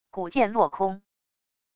骨箭落空wav音频